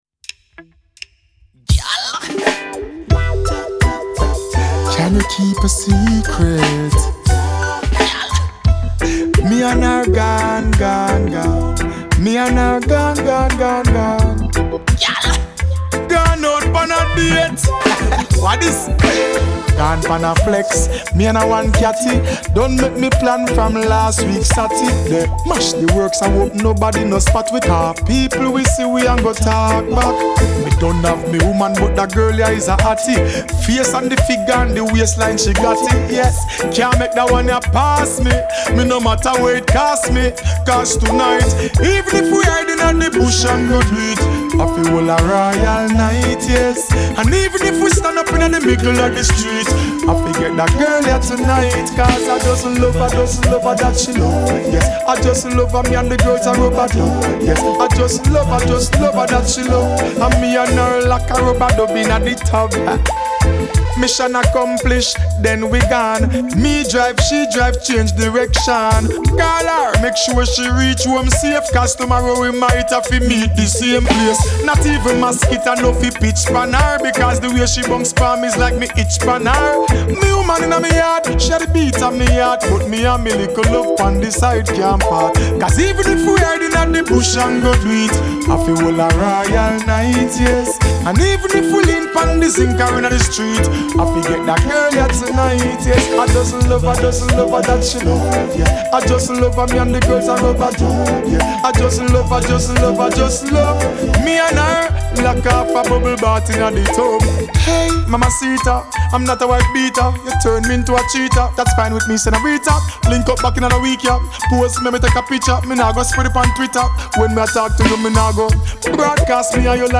Reggae RiddimRiddim